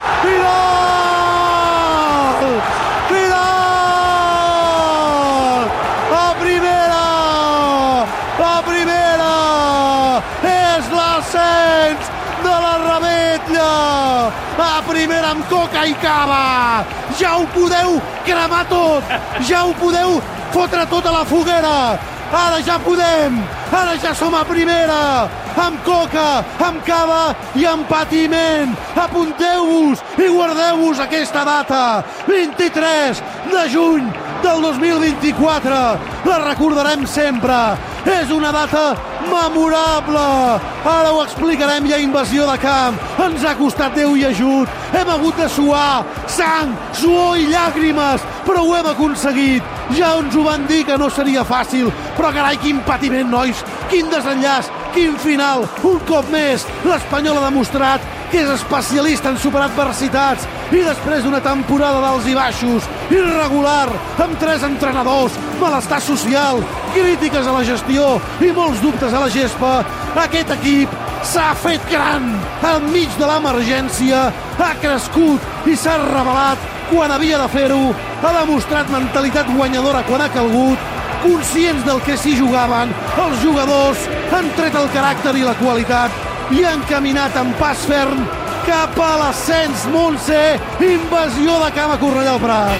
Transmissió del final del partit entre el RCD Espanyol i el Real Oviedo on l'Espanyol puja a la Primera Divisió de la Lliga de futbol masculí. Transmissió de la invasió de camp i resum dels darrers temps difícils de l'equip.
Esportiu